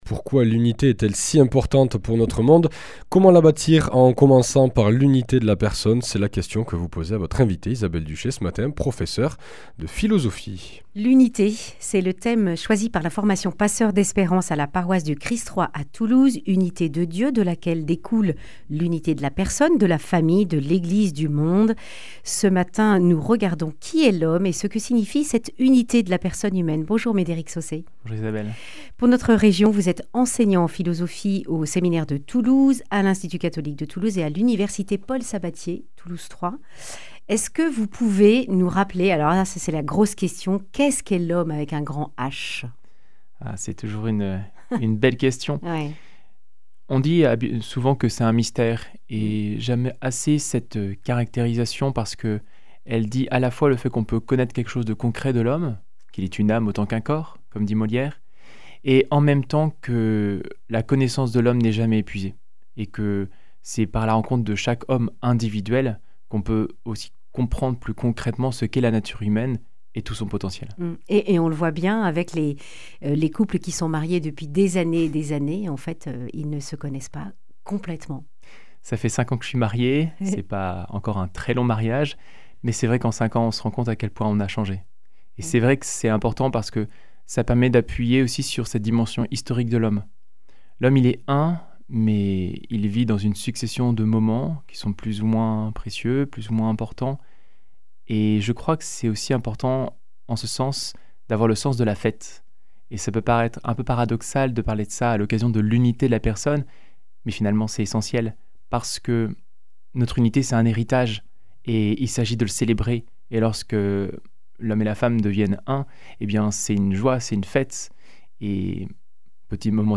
Accueil \ Emissions \ Information \ Régionale \ Le grand entretien \ Comment se construit l’unité de la personne humaine ?